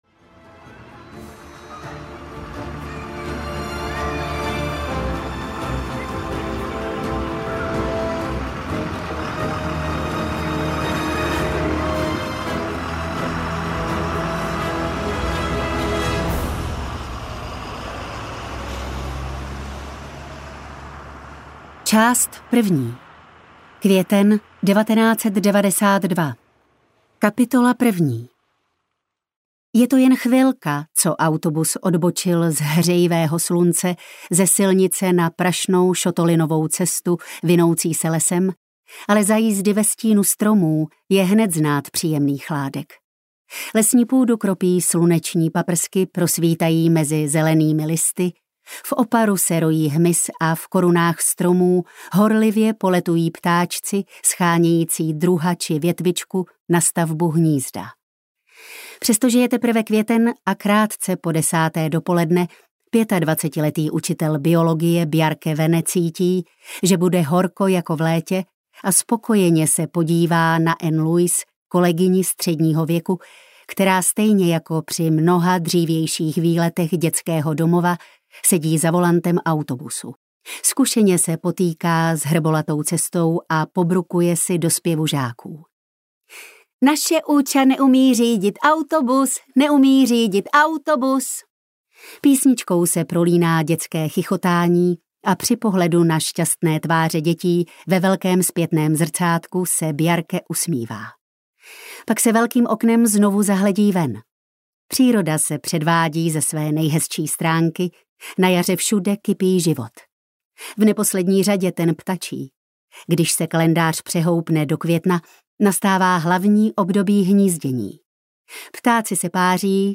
Ptáčátko audiokniha
Ukázka z knihy